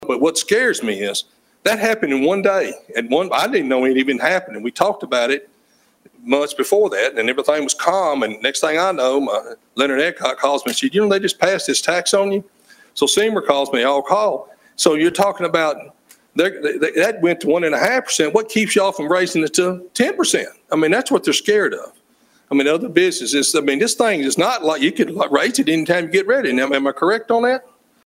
Agriculture leaders and related industries addressed Hopkinsville City Council about the issue Tuesday night.